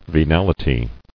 [ve·nal·i·ty]